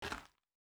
Shoe Step Gravel Medium D.wav